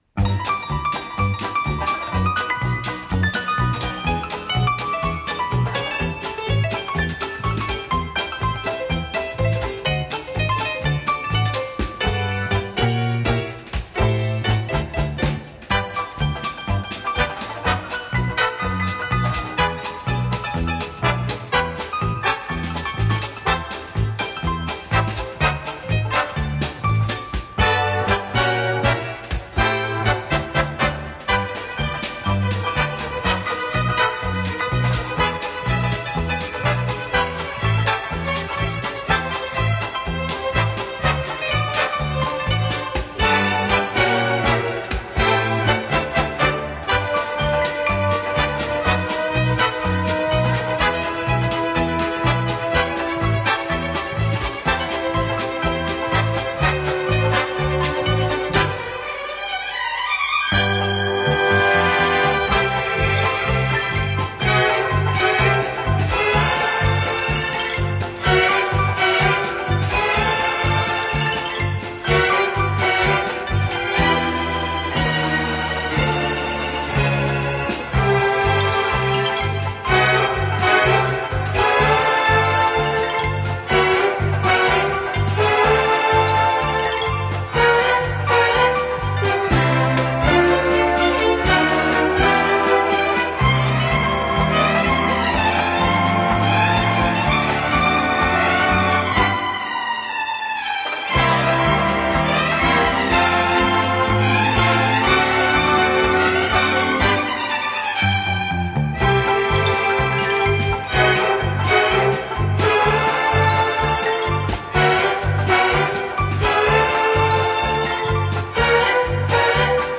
Жанр: Easy Listening